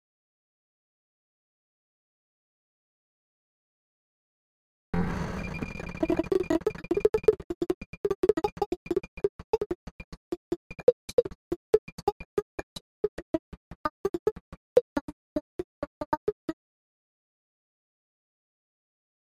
以下の例では、next beatを取得してフレーズを生成している。